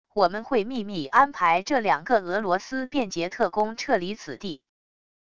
我们会秘密安排这两个俄罗斯变节特工撤离此地wav音频生成系统WAV Audio Player